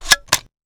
weapon_foley_drop_11.wav